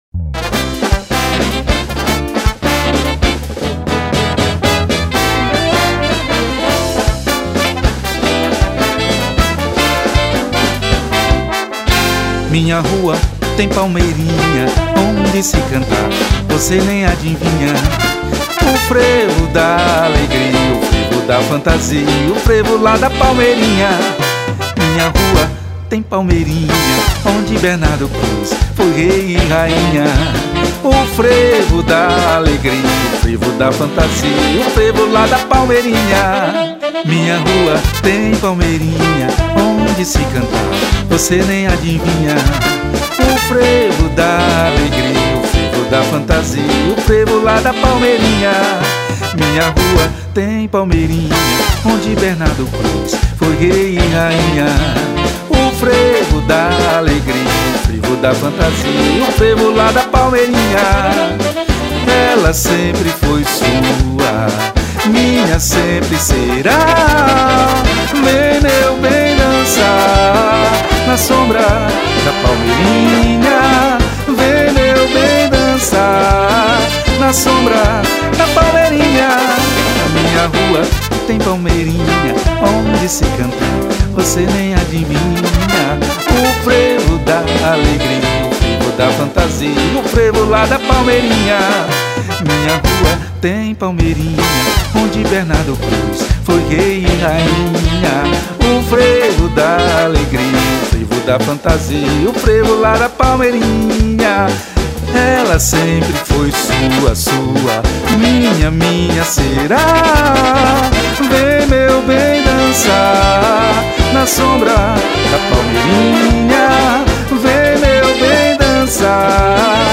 596   03:20:00   Faixa:     Frevo